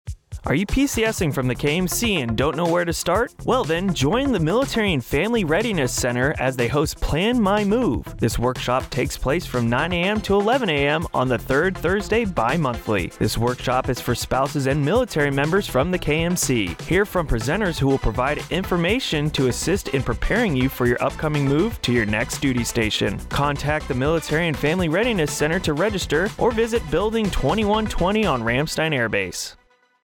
Commercial Spot - Plan My Move